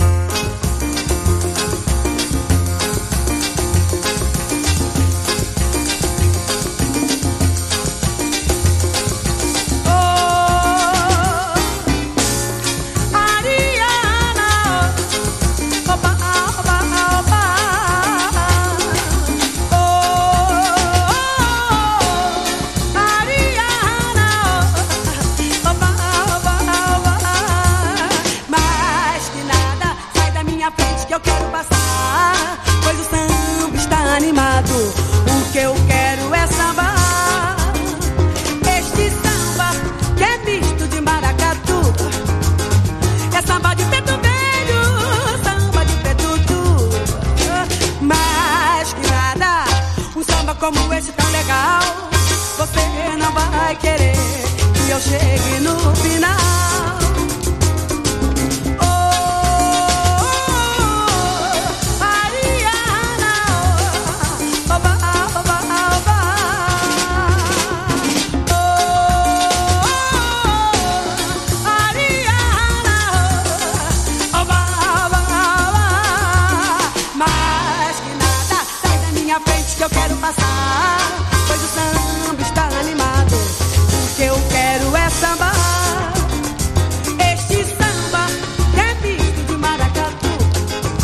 スレあるため試聴でご確認ください